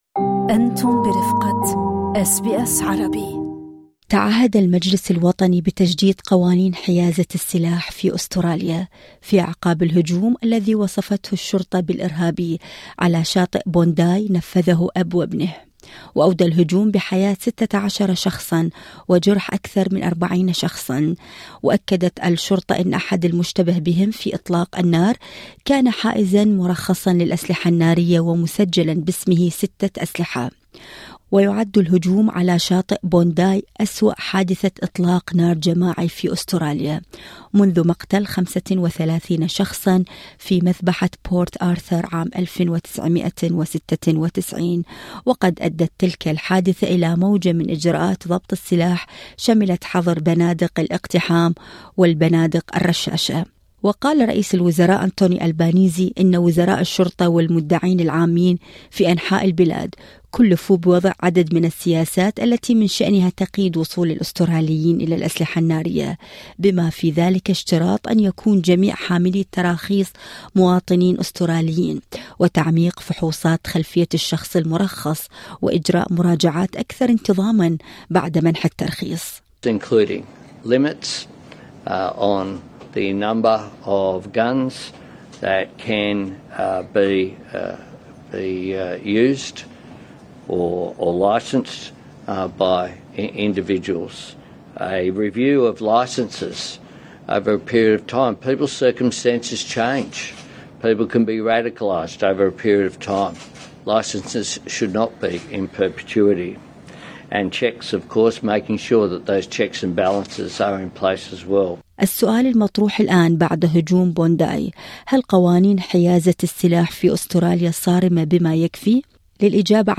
محامٍ يشرح قوانين حيازة السلاح في أستراليا وأبرز التغييرات التي تطرأ عليها